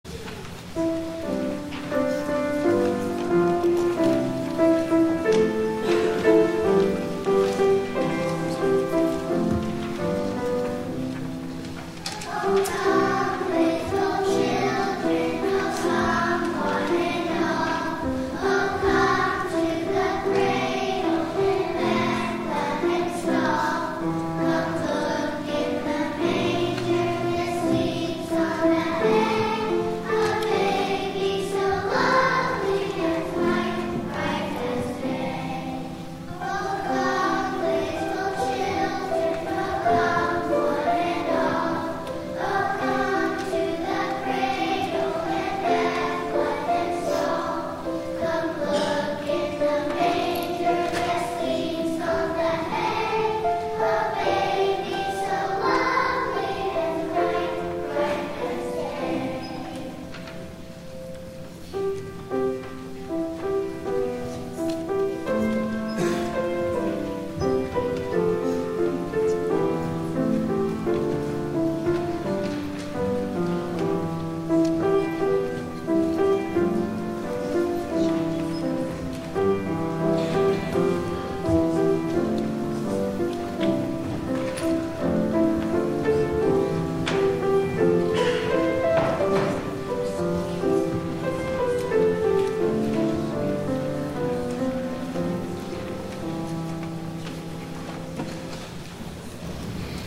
11 A.M. WORSHIP
THE CHILDREN’S ANTHEM
The Children’s Choirs